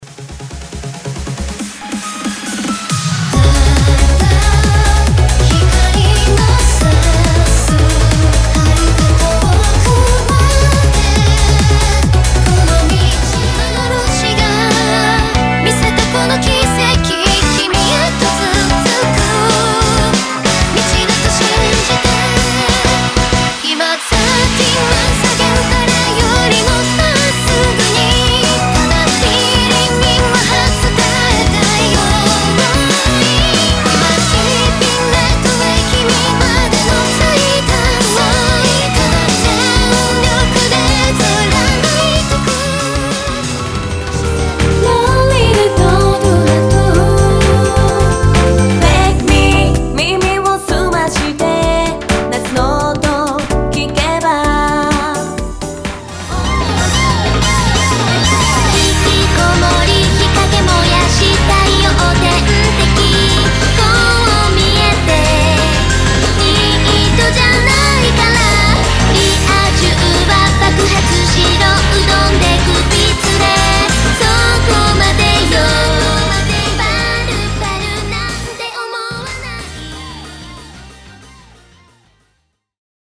EUROBEAT
EDM 　ワクワクする見慣れた幻想郷 　バレットフィリア達の闇市場